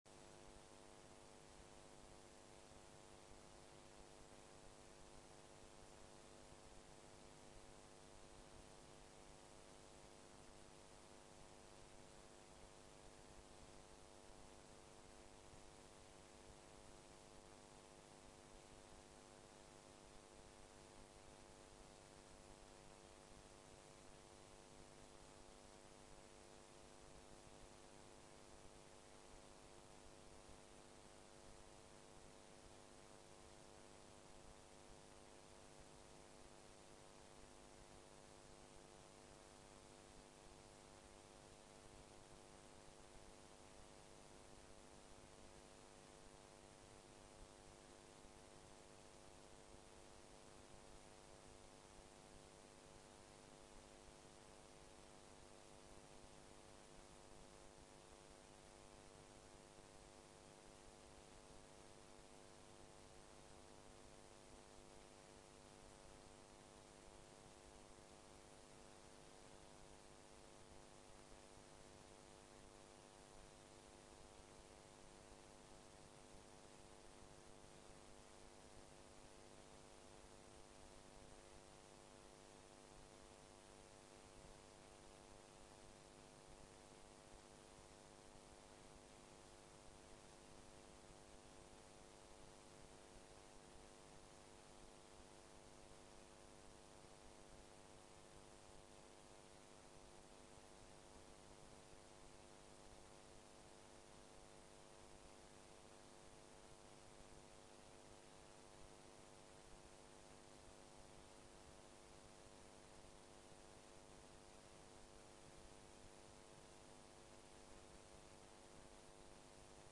Rom: K105